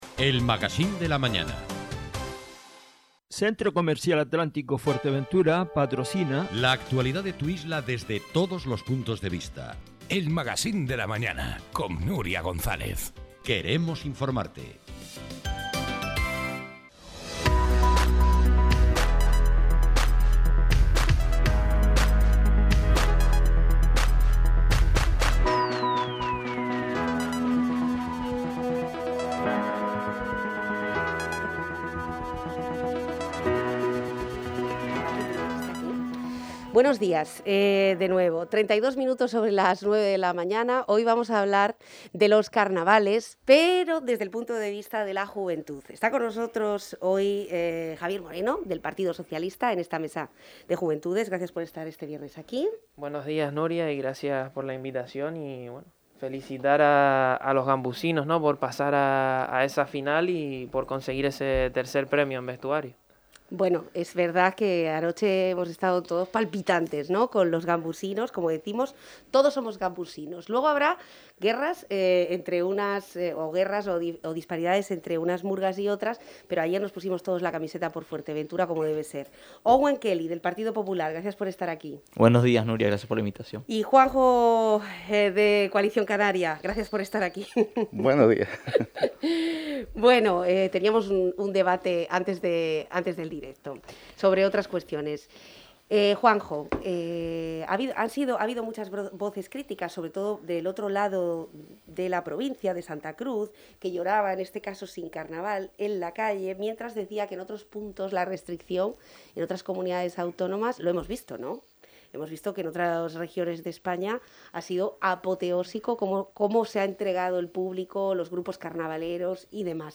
También disfrutan del carnaval y lo han comentado en el debate como también han hablado de la necesidad de ir abriendo más a los actos carnavaleros, con las medidas sanitarias, pero ir volviendo a la normalidad.